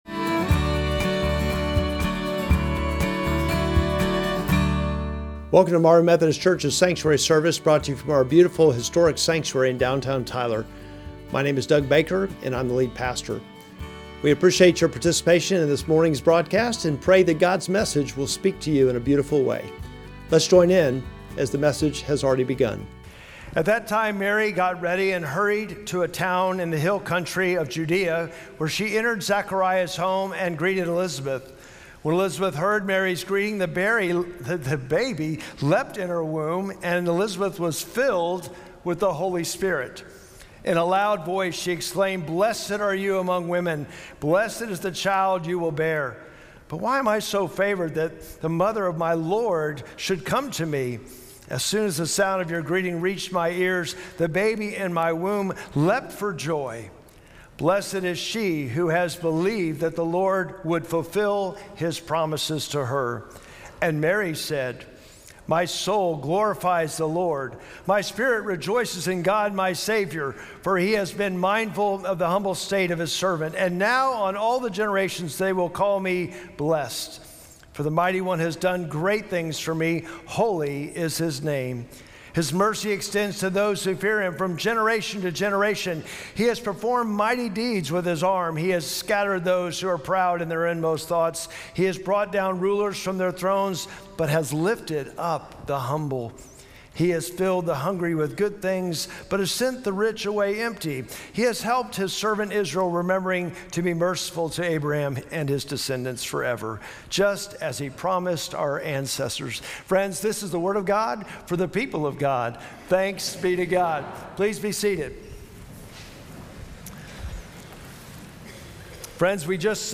Sermon text: Luke 1:39-56